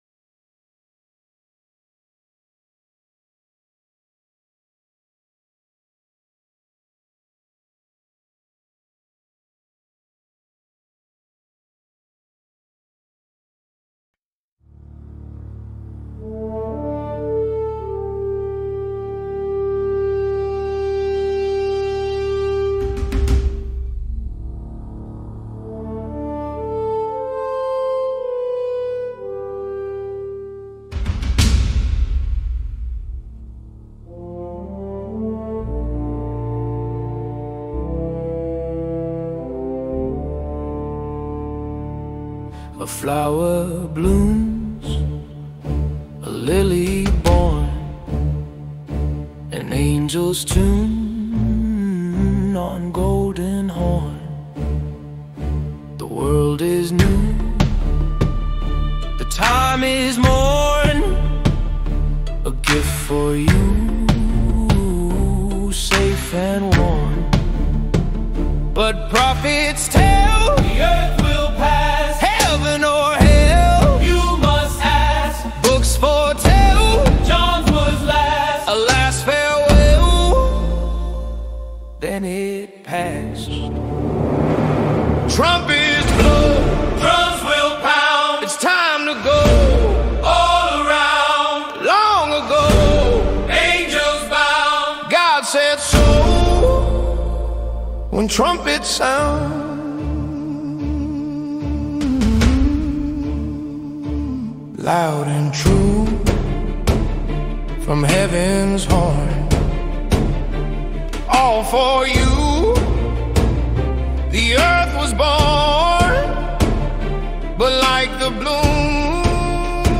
AI(Music)